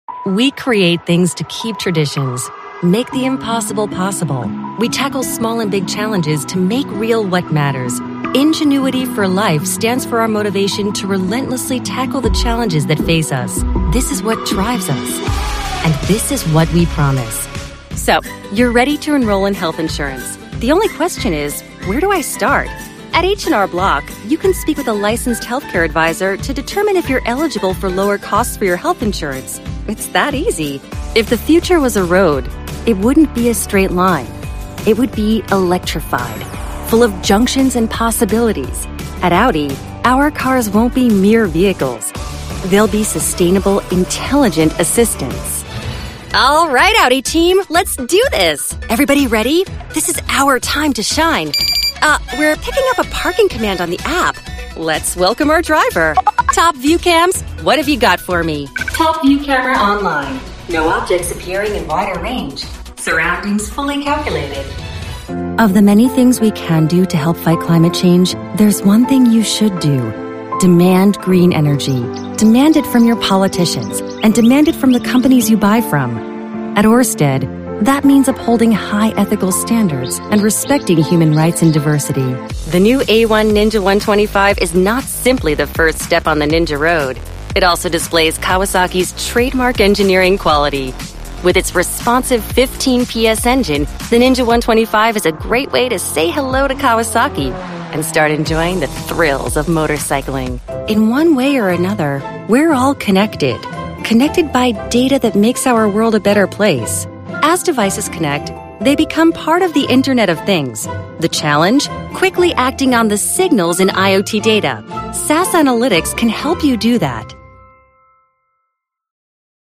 Comedic and savvy a la Tina Fey, Jenny Slate, Sarah Silverman.
Corporate Narration Demo
Young Adult
Middle Aged